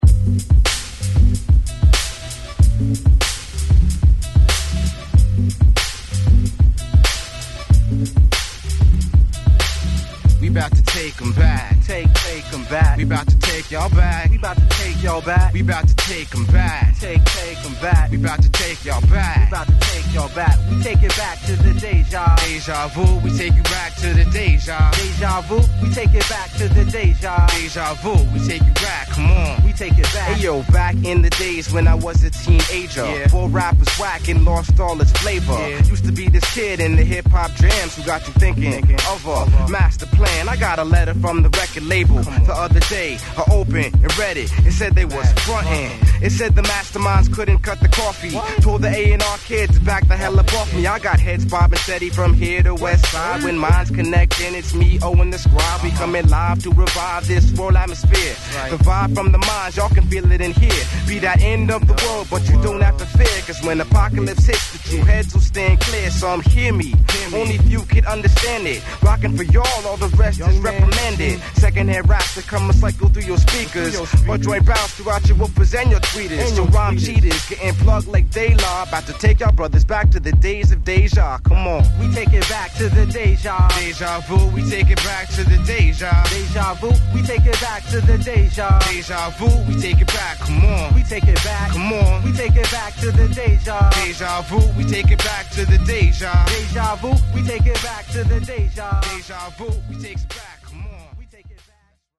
90's NY Underground Hip Hop!!